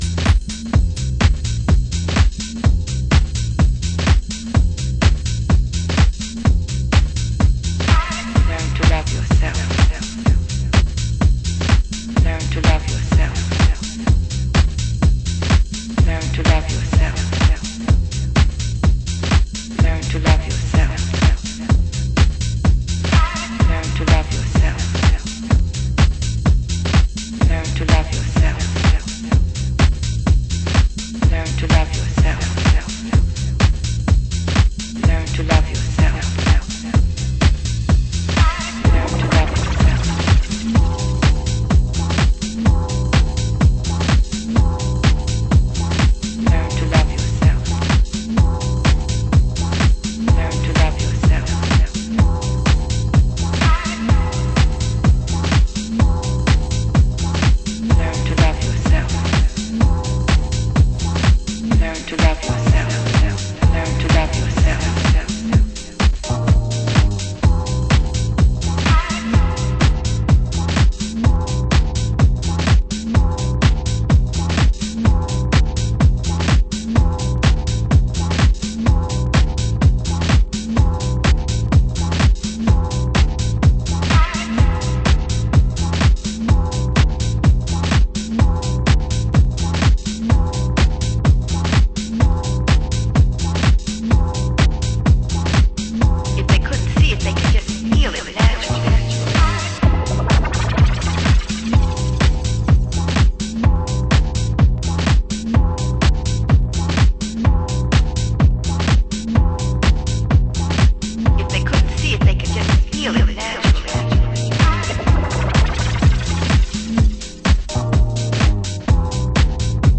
HOUSE MUSIC
盤質：小傷有/少しチリパチノイズ有